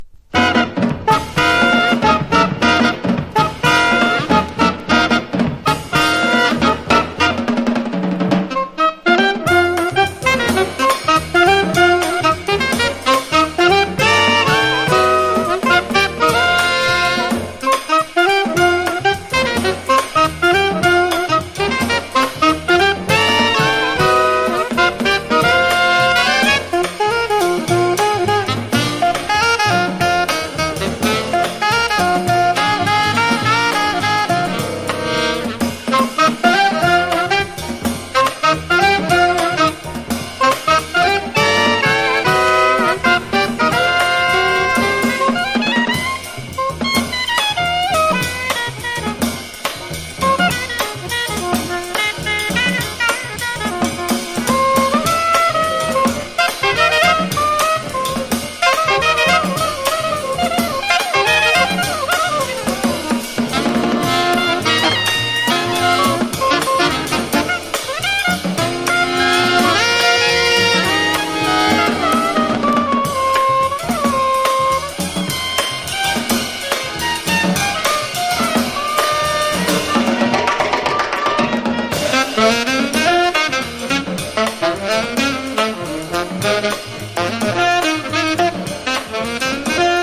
キューバン・バップ・スタイルのスウィンギン・フロア・キラーA1収録！